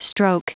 Prononciation du mot stroke en anglais (fichier audio)
Prononciation du mot : stroke